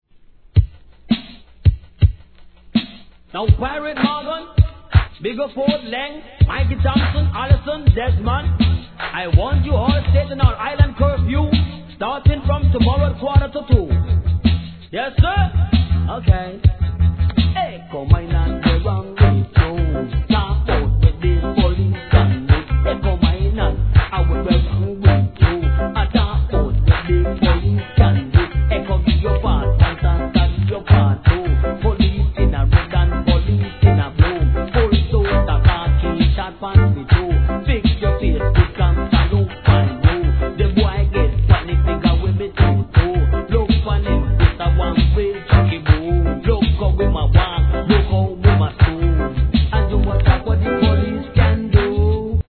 1. REGGAE